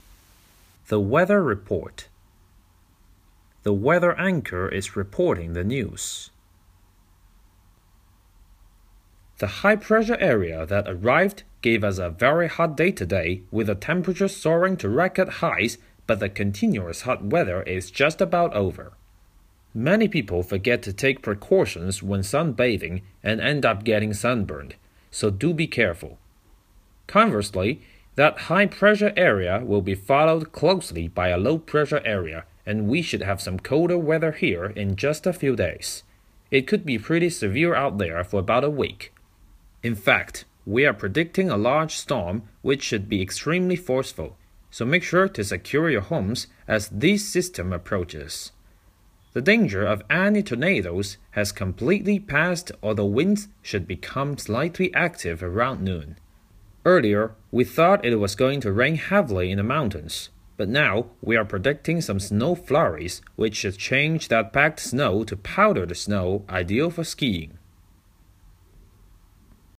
The weather anchor is reporting the news.